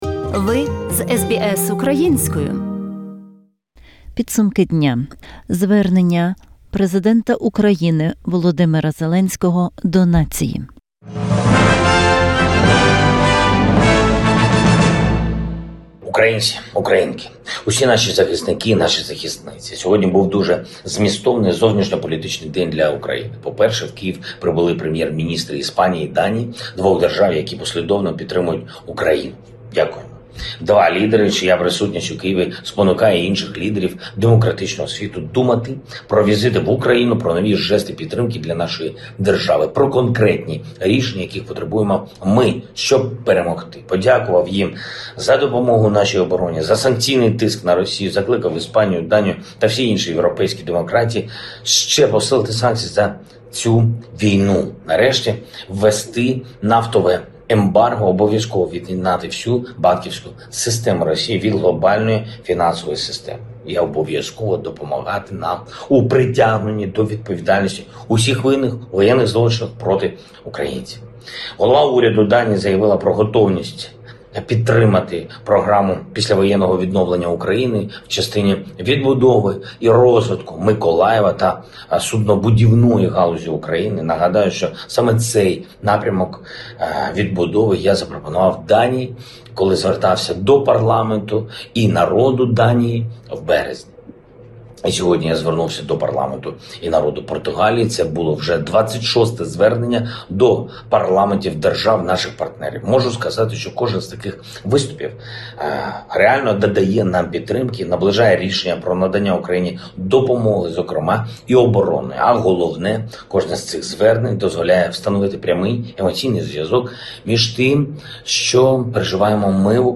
Address of the President of Ukraine Volodymyr Zelenskyy.